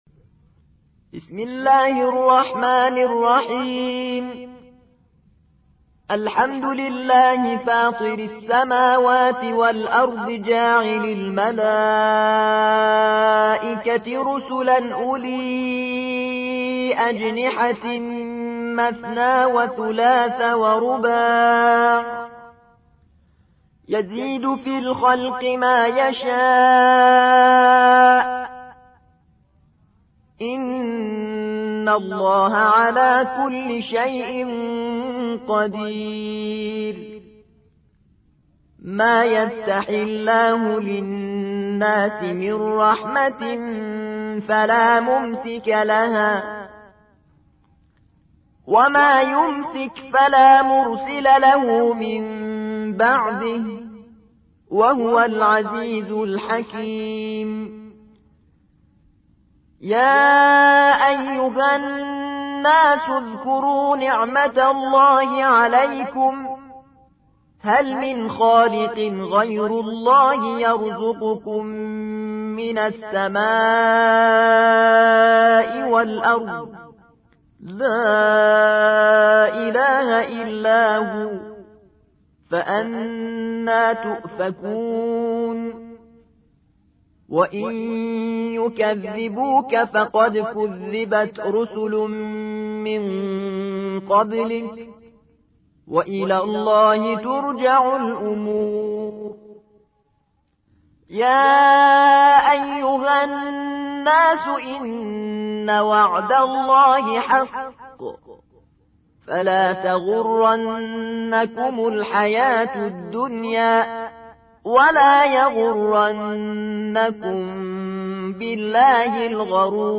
35. سورة فاطر / القارئ